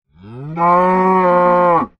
Sound / Minecraft / mob / cow / say3.ogg